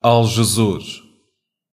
Aljezur (Portuguese pronunciation: [alʒɨˈzuɾ]